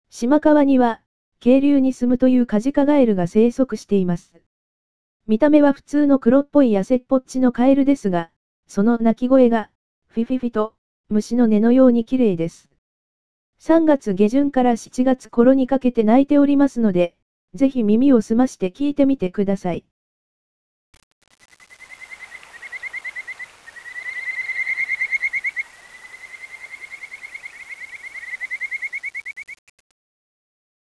カジカガエル – 四万温泉音声ガイド（四万温泉協会）